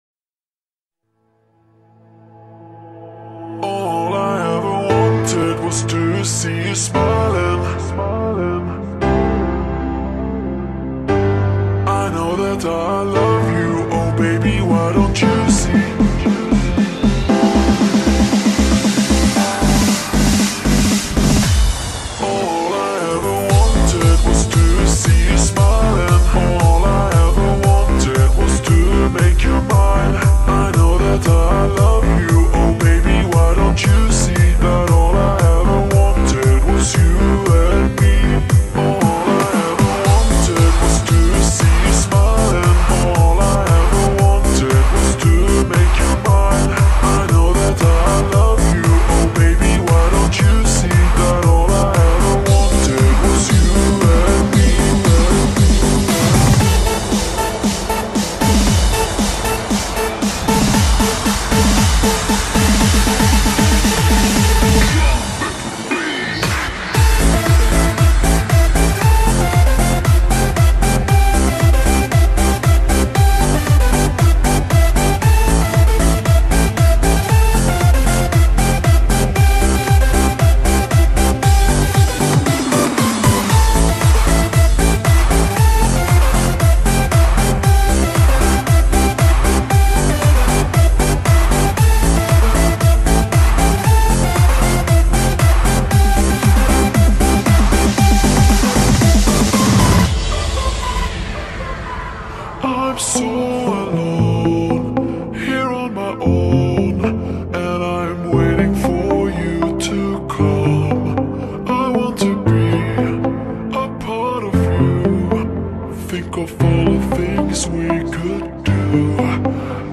شاد